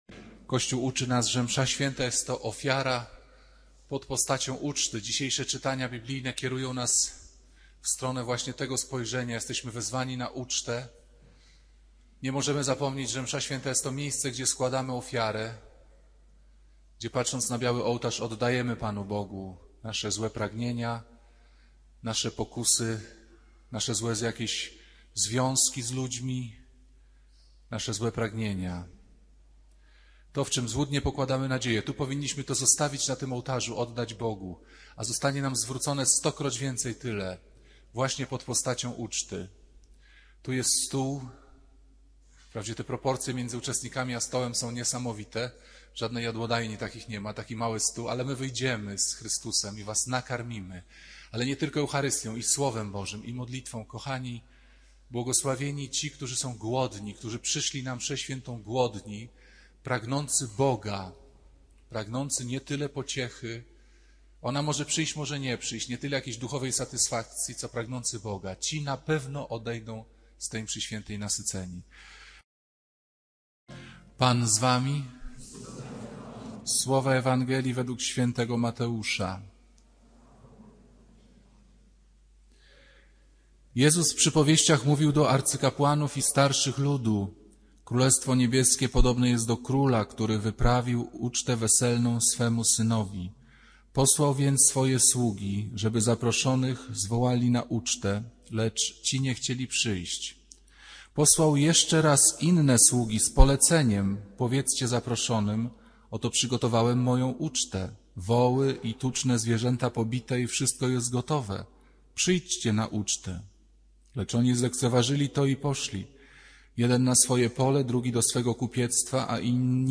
Ks. Piotr Pawlukiewicz z pasją i trafnością diagnozuje współczesne źródła duchowego pobudzenia i iluzorycznego życia.
Przypomina, że Msza Święta to nie tylko uczta duchowa, ale także wezwanie do głębokiej przemiany serca i porzucenia fałszywych nadziei. Kazanie dotyka problemów pustki, jaką próbujemy zapełnić technologią, seksem czy prowokacją religijną.